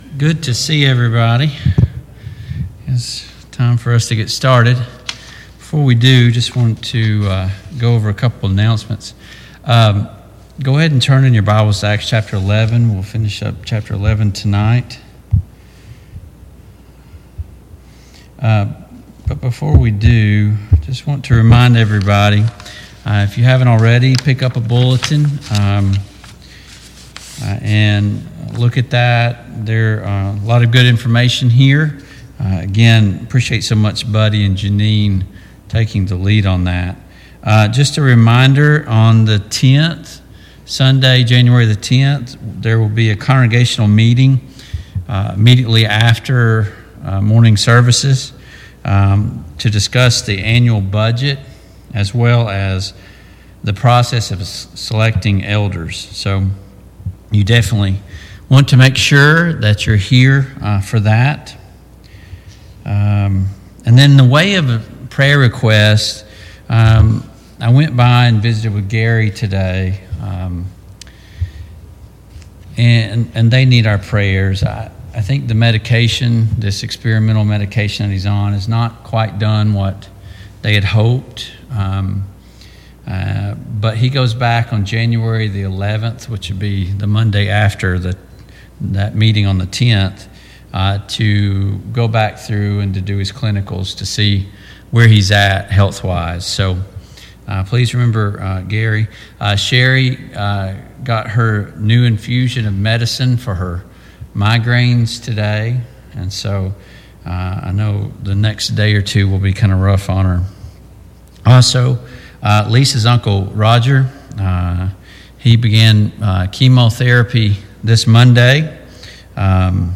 Service Type: Mid-Week Bible Study